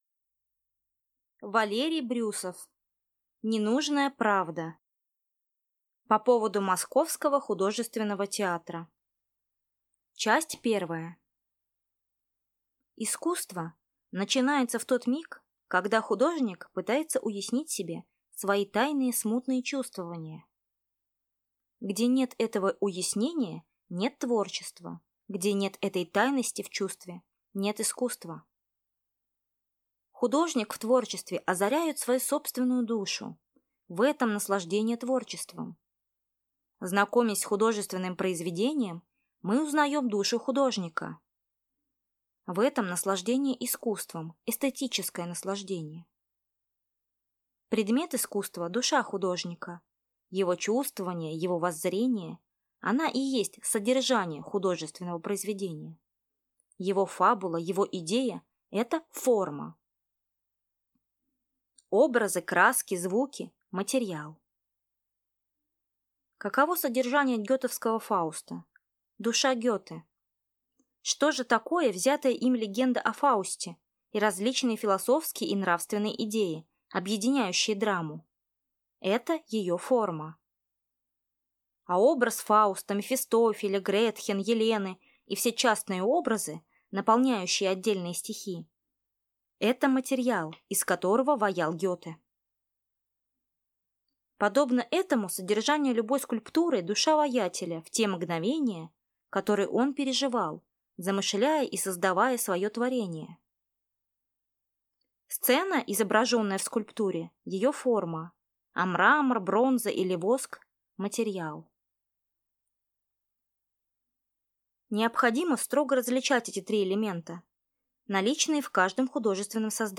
Аудиокнига Ненужная правда | Библиотека аудиокниг